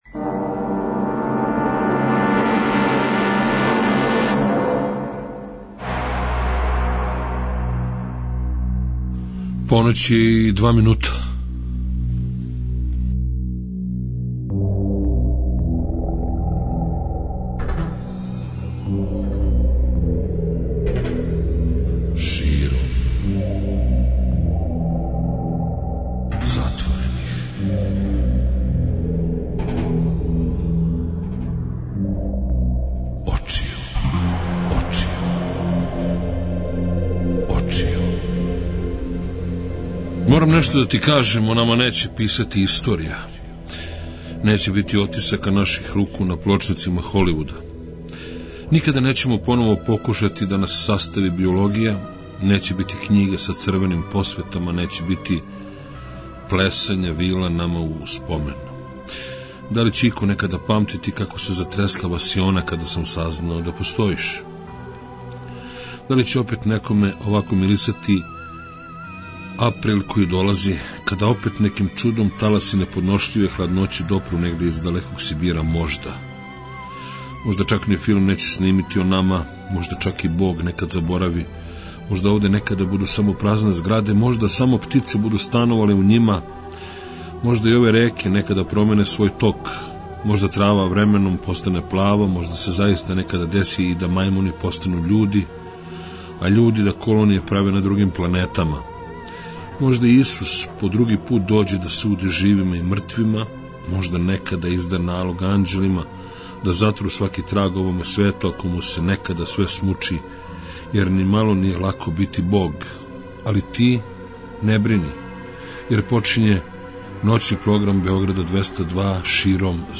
Биће то ноћ дивних нота, лепих речи, мисли, и још један малени доказ да Бајке никада неће изаћи из моде.